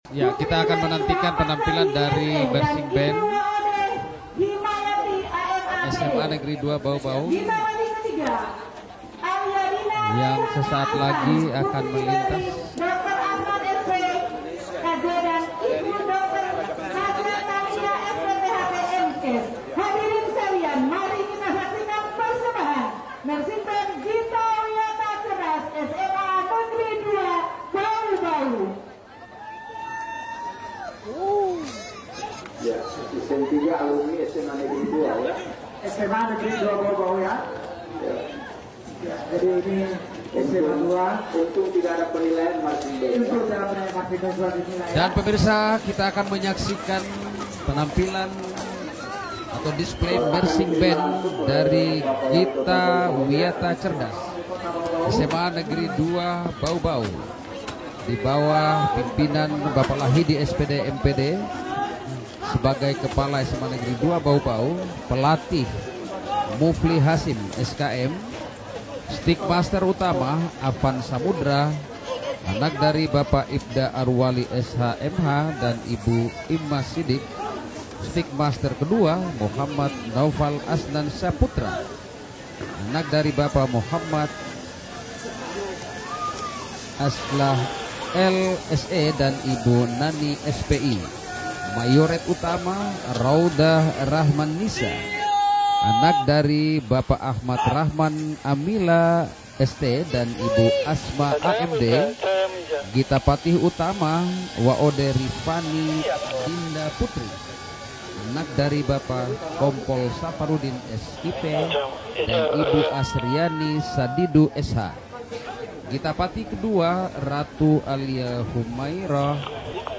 Marching band smada baubau full